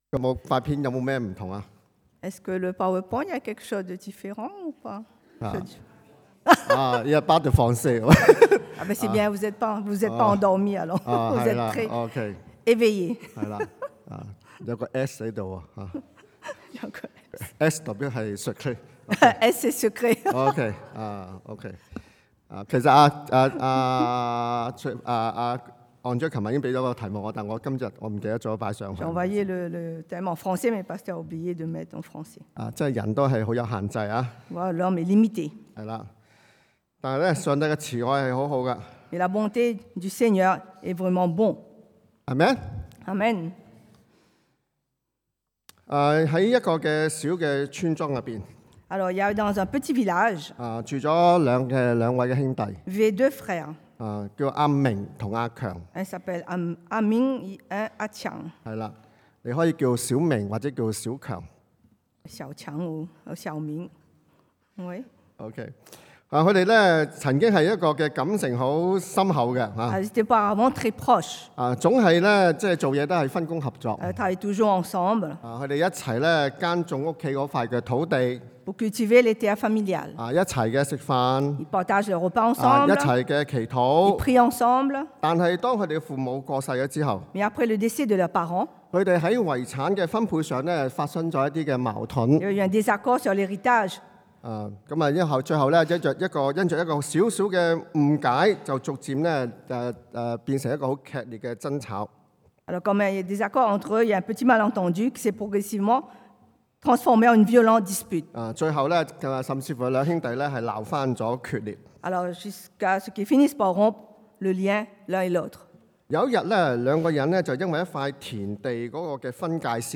Passage: Ephésiens 以弗所書 2:14-18 Type De Service: Predication du dimanche